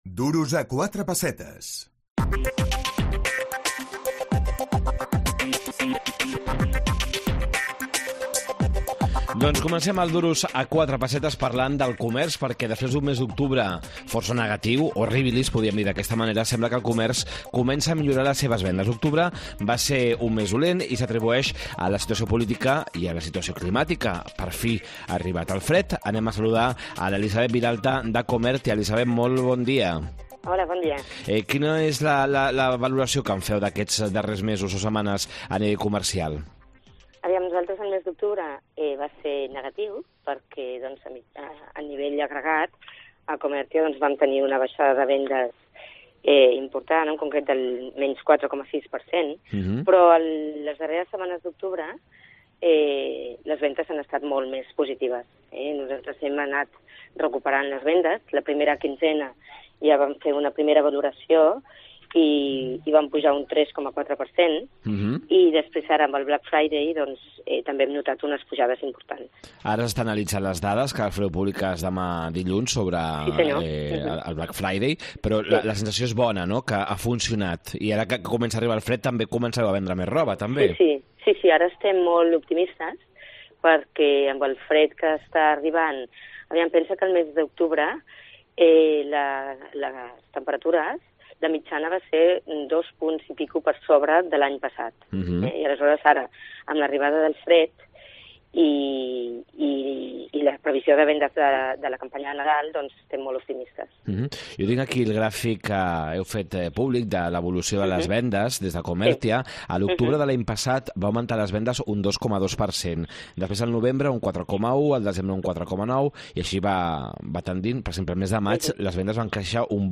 AUDIO: Cauen les vendes al comerç català. Entrevista